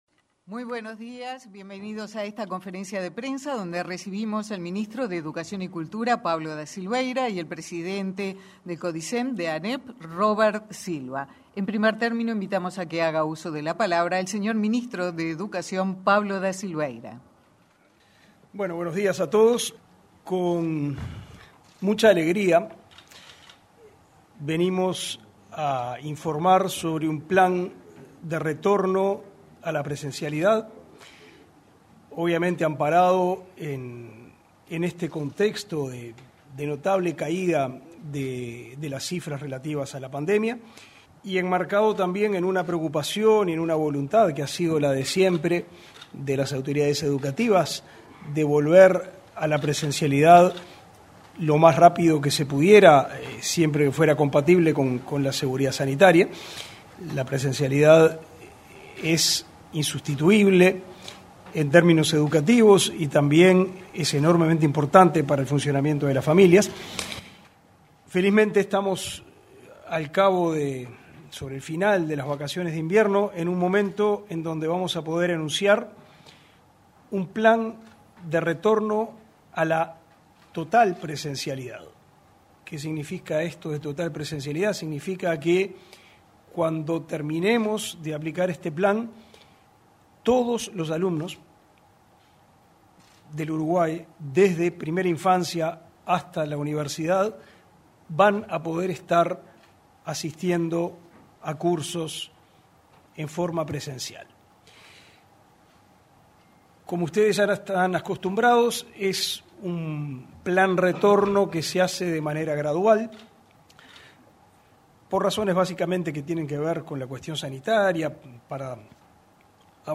Conferencia de prensa retorno a la presencialidad Educación Media
Conferencia de prensa retorno a la presencialidad Educación Media 06/07/2021 Compartir Facebook X Copiar enlace WhatsApp LinkedIn El ministro de Educación y Cultura, Pablo da Silveira y el presidente de la ANEP, Robert Silva realizaron una conferencia de prensa en la Torre Ejecutiva este martes 6. Se anunció que el lunes 12 de julio comenzará el retorno progresivo de los estudiantes de Educación Media a las aulas de todo el país.